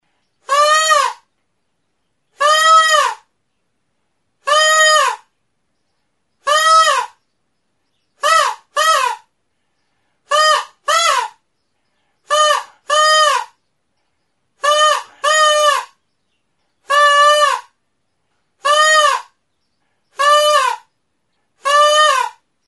Aerophones -> Reeds -> Single Free
Recorded with this music instrument.
Hurritz makila bat da, luzera erditik ebakia eta bi zatien artean bizikleta gomazko neumatiko puska bat tinkaturik.